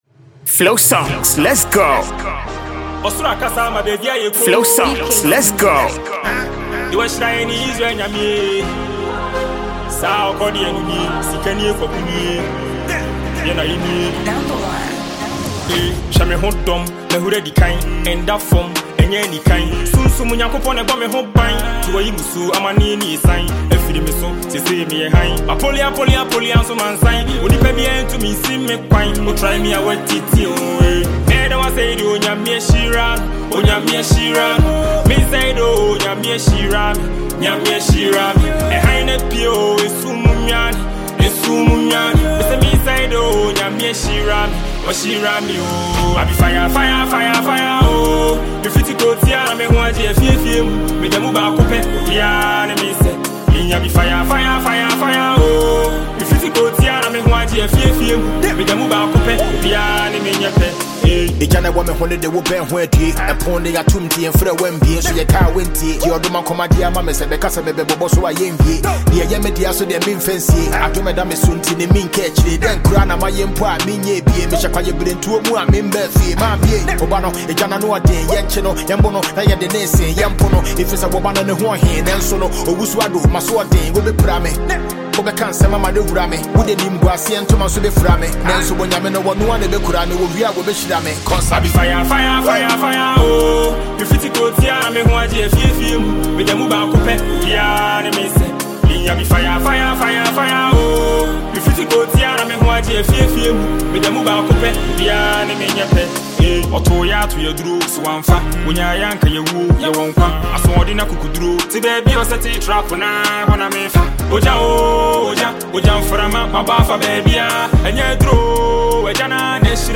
Ghanaian Afrobeat musicians
It is a catchy and lively melody.
Highlife and Afrobeat